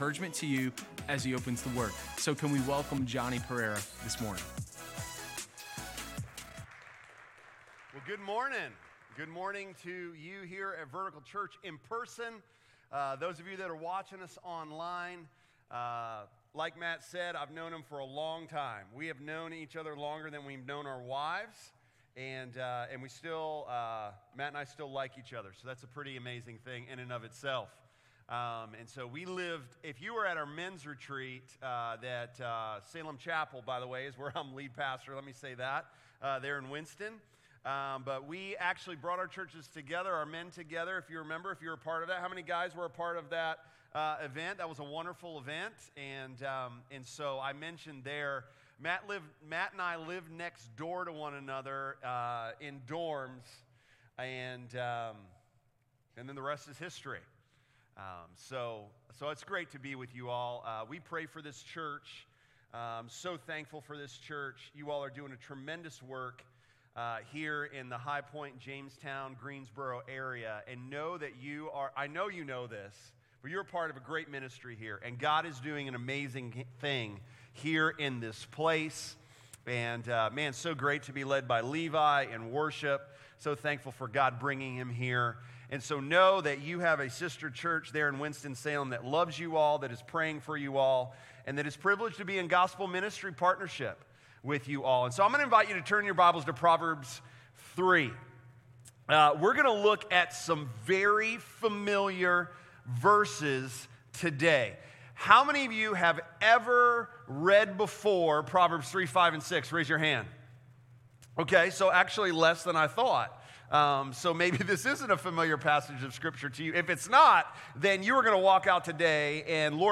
Sermon03_21_Gods-Promise-of-Direction.m4a